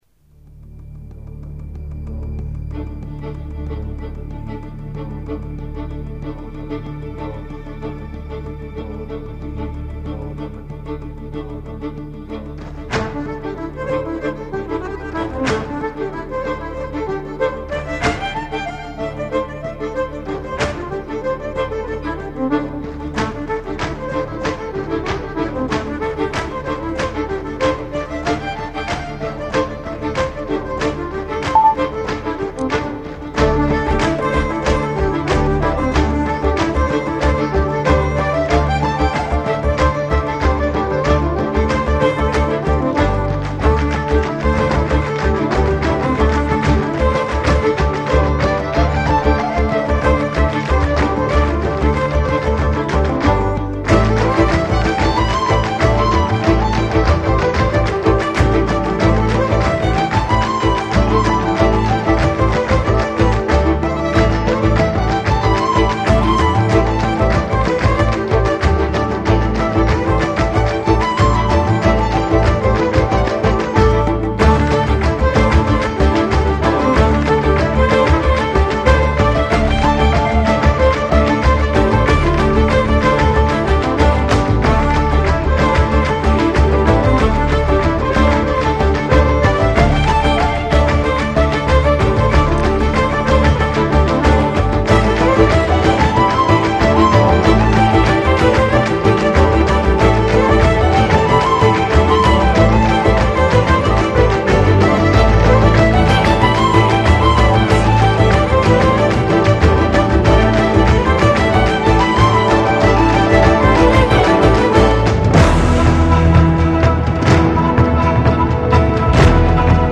纯音乐欣赏